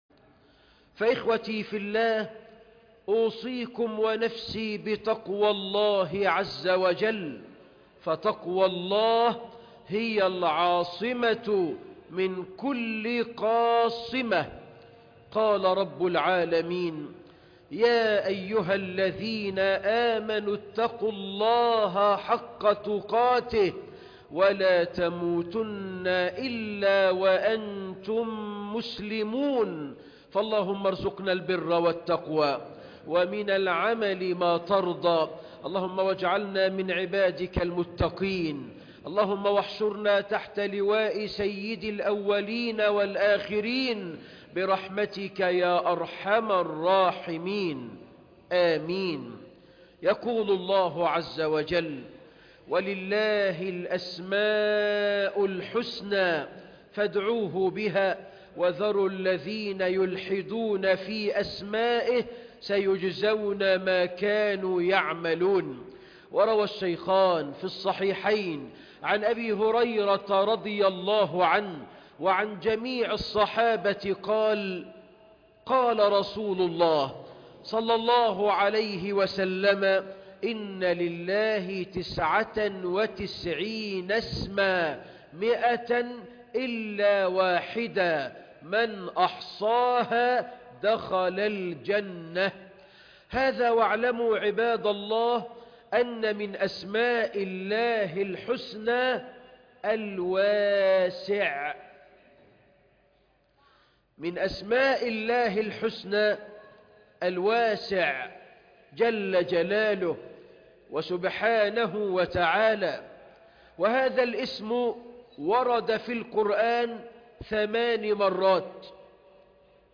الخطبة كاملة - شرح أسم الله الواسع